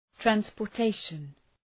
Shkrimi fonetik{,trænspər’teıʃən}
transportation.mp3